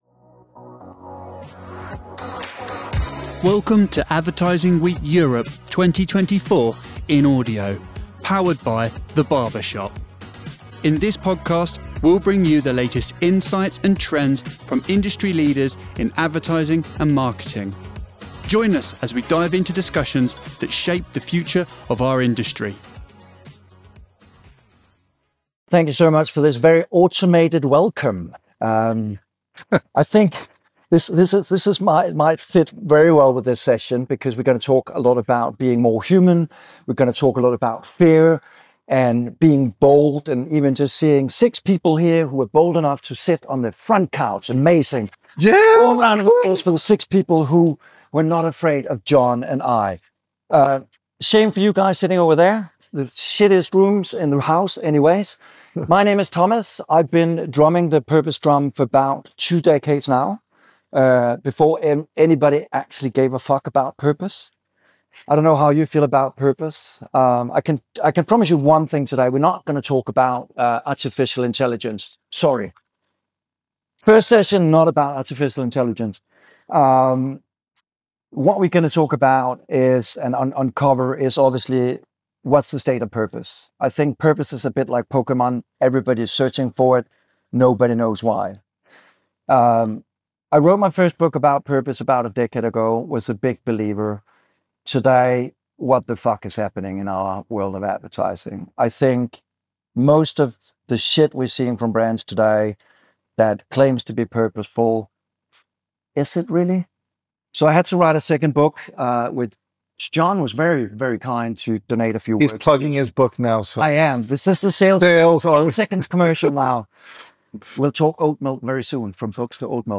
This session highlights Oatly's creative strategies and forceful responses to emerging trends. Learn about their commitment to sustainability, the challenges they face, and how they maintain brand relevance. The discussion provides valuable lessons for brands navigating the complexities of purpose-driven marketing.